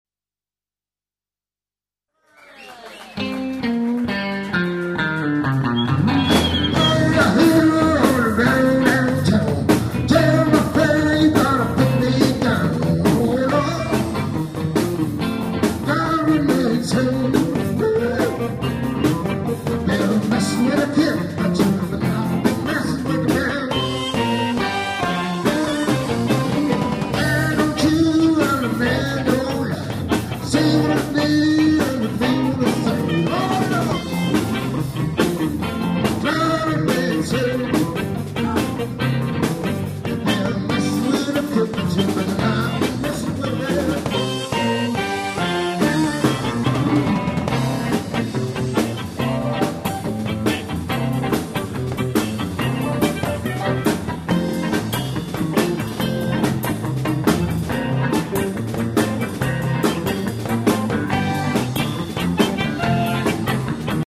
harmonicas
Des extraits, (30 secondes environ) du concert enregistré le 3 Mars 2000
au Relais de la Reine Margot (Longvic, Côte d'or) :